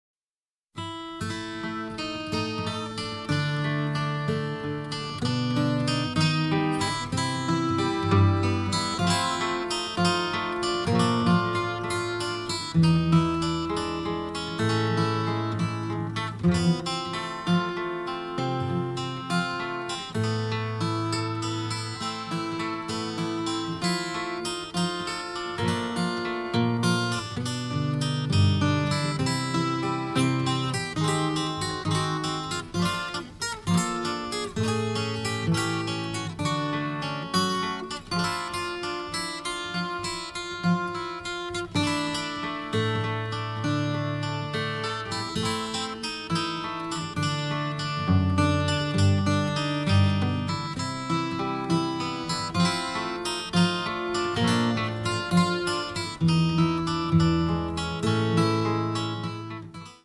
giallo score